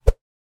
Download Woosh sound effect for free.
Woosh